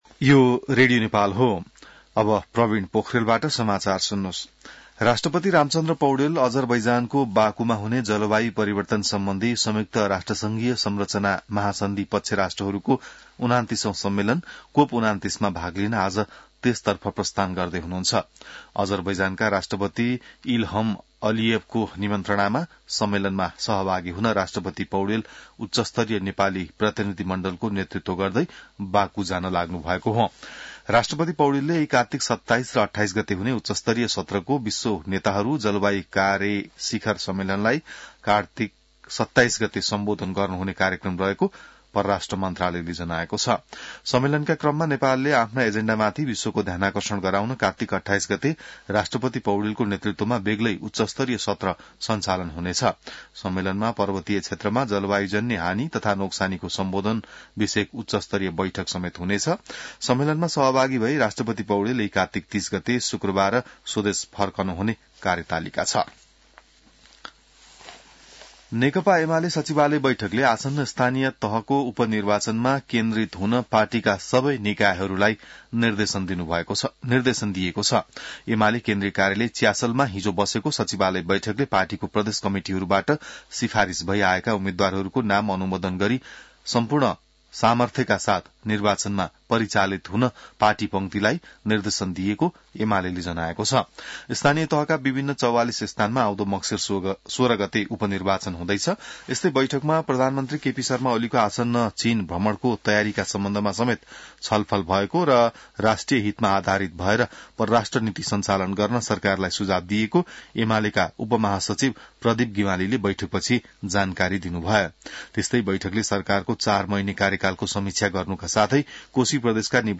बिहान ६ बजेको नेपाली समाचार : २६ कार्तिक , २०८१